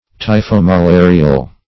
Search Result for " typhomalarial" : The Collaborative International Dictionary of English v.0.48: Typhomalarial \Ty`pho*ma*la"ri*al\ (t[imac]`f[-o]*m[.a]*l[=a]*r[i^]*al), a. (Med.)